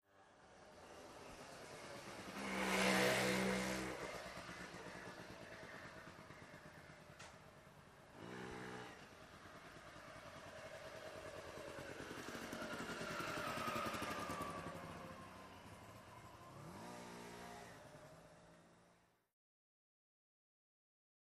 Motor Scooter, By Slow, Cu, Return Slow.